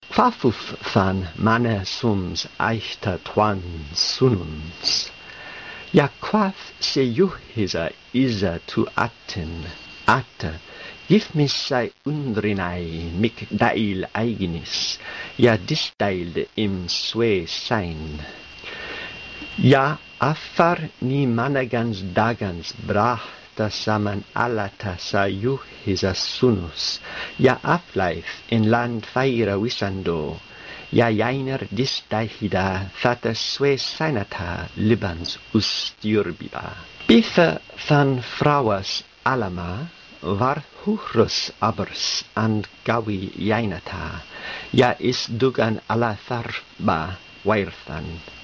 18 August 2013 at 10:27 pm The clip is a reading of Wulfila’s translation, not Crimean Gothic.
19 August 2013 at 12:50 am I have the impression from the rhythm and intonation that this isn’t a native speaker.